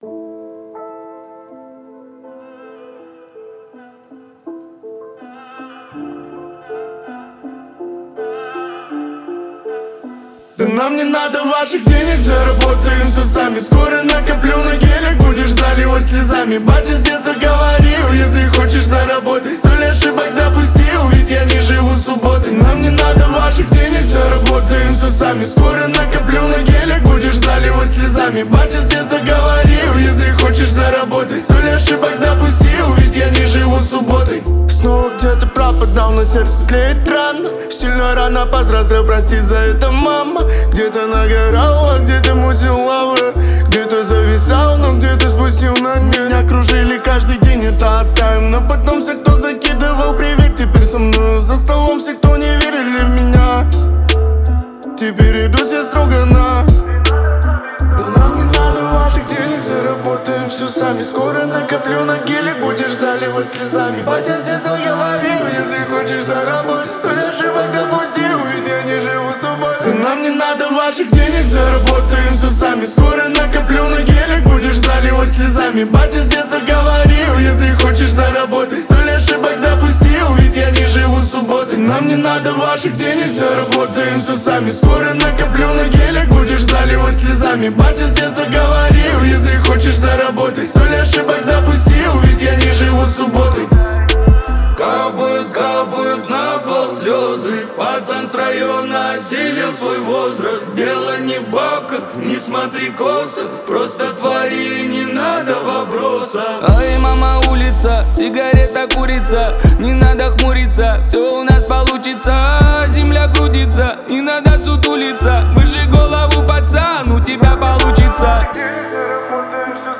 Жанр: Хип-хоп